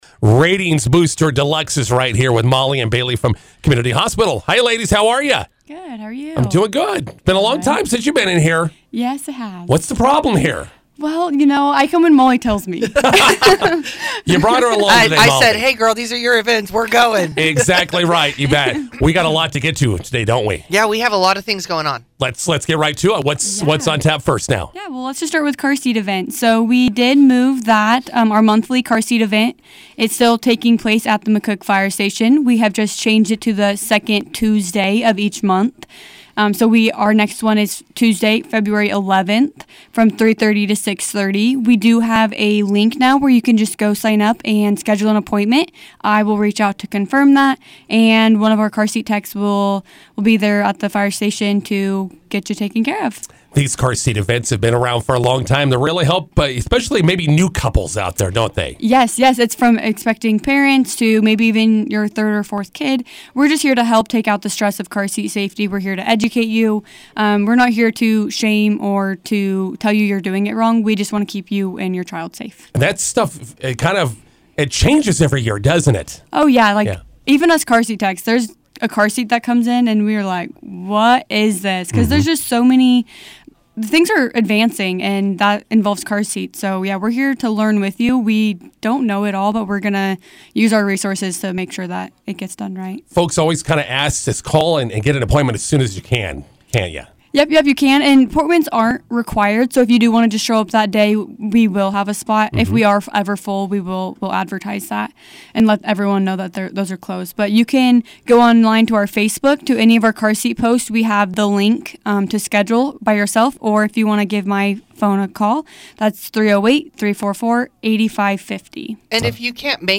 INTERVIEW: Community Hospital February-March events are coming up.